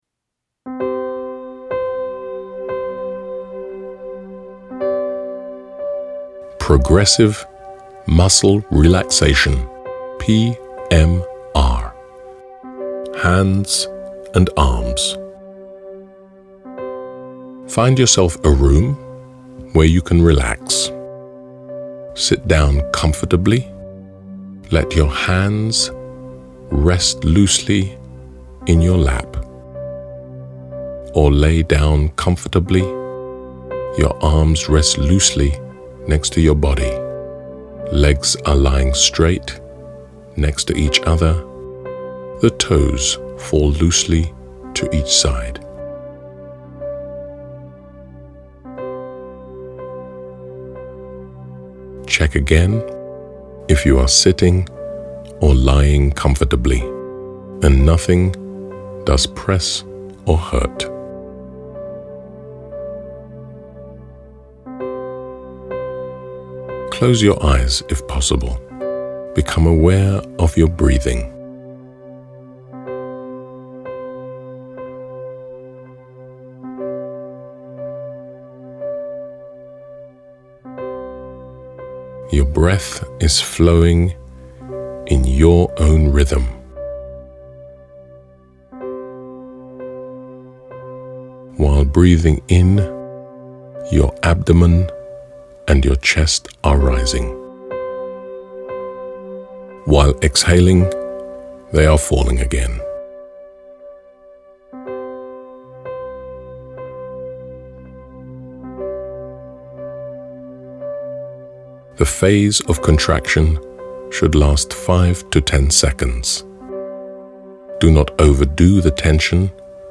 Test us SyncSouls offers the complete Exercise: Progressive Muscle Relaxation Body region: hands and arms (well applicable in case of increased desk work) from the audiobook Progressive Muscle Relaxation as MP3 download file for free .
progressive-muscle-relaxation-arms-and-legs.mp3